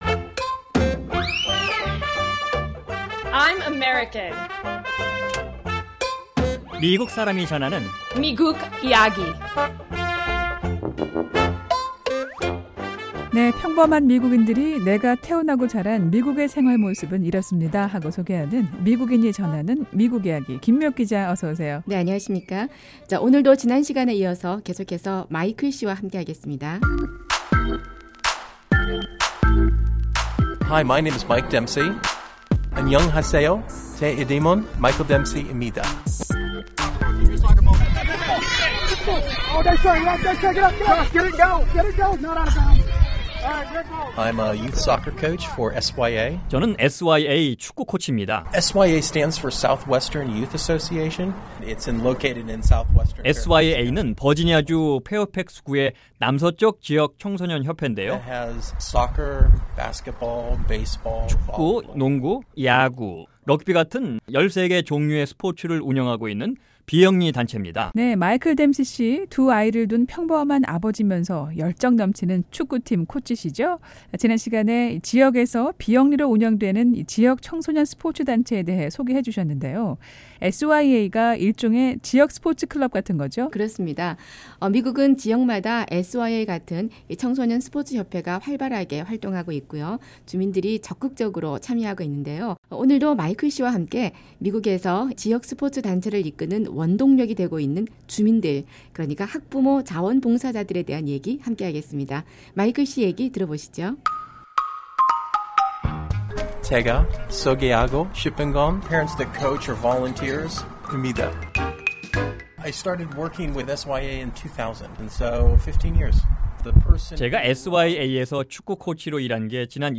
평범한 미국인이 전하는 미국의 생활 모습. 오늘은 미국 각 지역에서 청소년들을 위해 운영되는 스포츠팀과 여기에 자원봉사로 참여하는 학부모들의 역할 등을 알아봅니다.